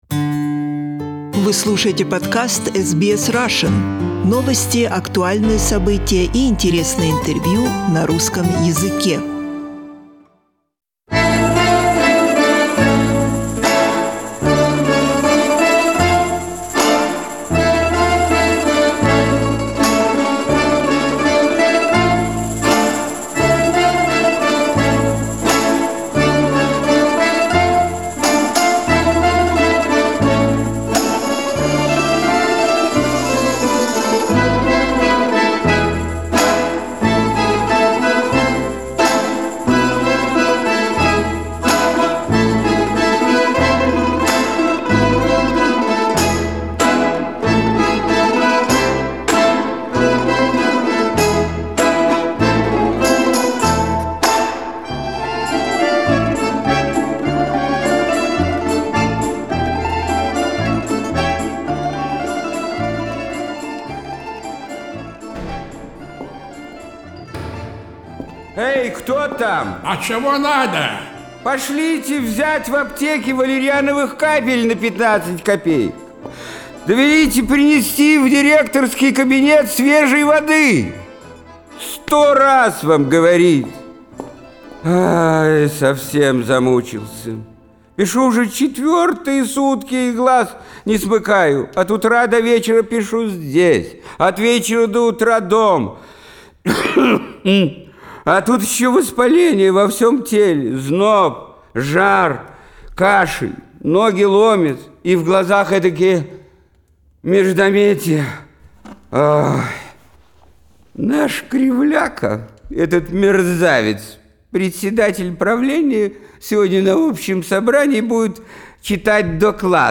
His energy attracted to the studio many amateur and professional actors of different ages from many different cities of the former USSR.